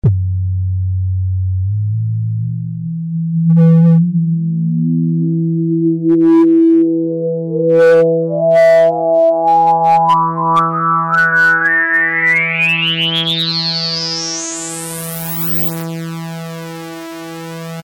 Basic_SINUS+zunehmendCutoff+maxResonance.mp3